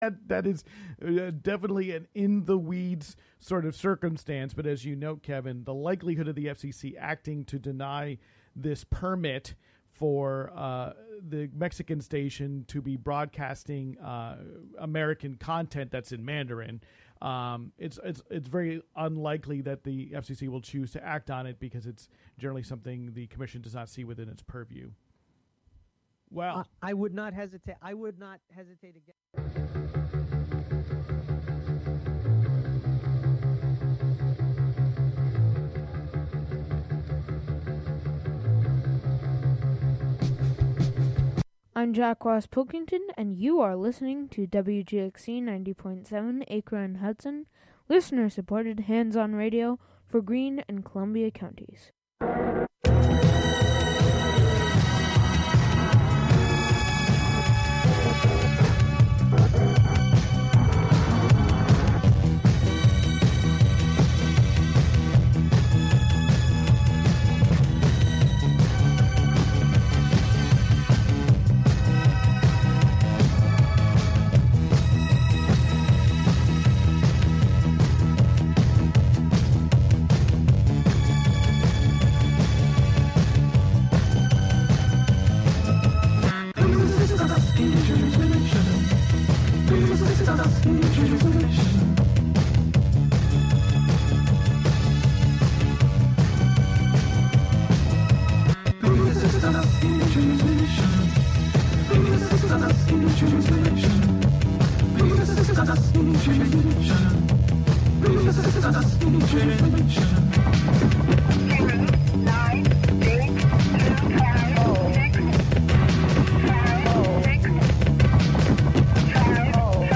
This week: S1 EP6 - Coda - Beyond the Western Door: Magnetic on Main. Every season, we will probe deeper into the mysteries of the storyline through a mystical sound and text experiment, plunging us beyond language, beyond narrative, Beyond the Western Door.